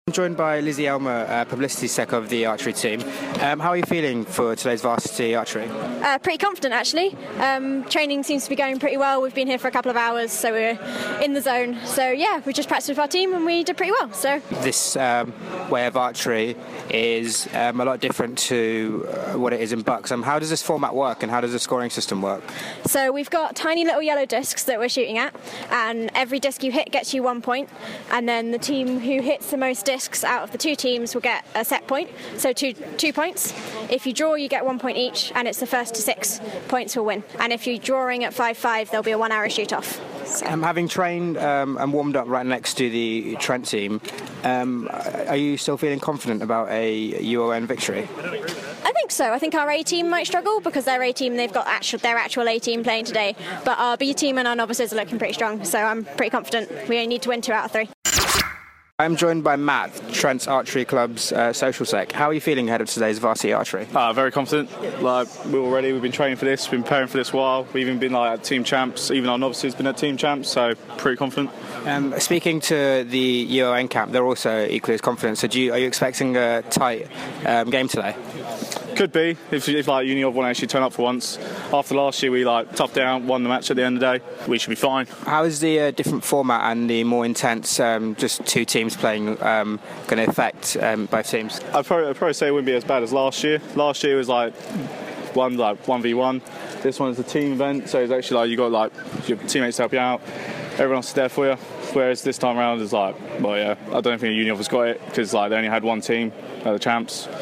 Varsity 2017 - Archery pre-match interviews